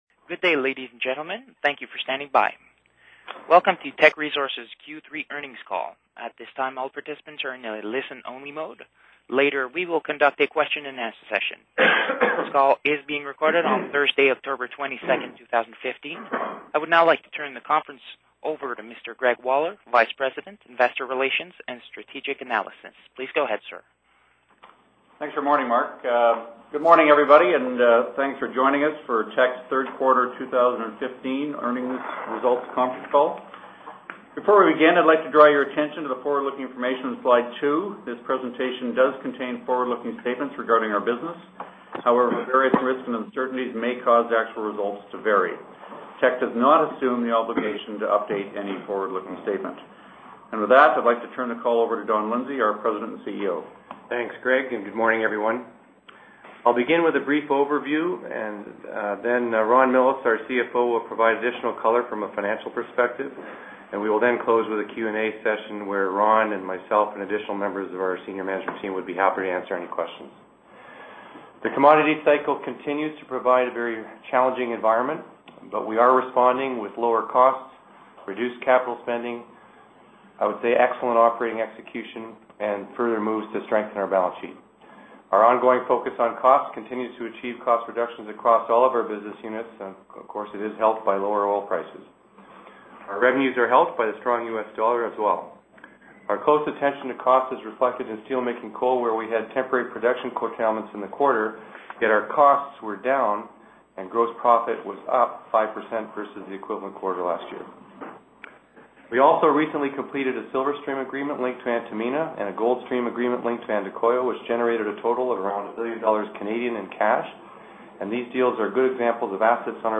Q3 Financial Report [PDF - 0.29 MB] Q3 Financial Report Presentation Slides [PDF - 1.49 MB] Q3 Financial Report Conference Call Transcript [PDF - 0.31 MB] Q3 Financial Report Conference Call Audio [ - 26.21 MB]